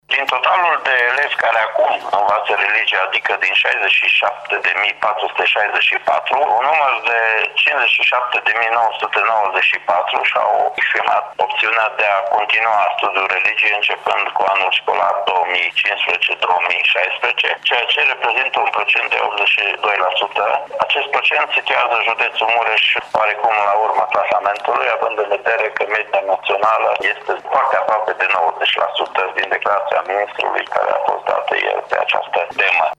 Judeţul Mureş se află la coada clasamentului elevilor care au optat pentru studierea religiei în şcoli a spus astăzi şeful Inspectoratului Şcolar Judeţean Mureş, Ştefan Someşan.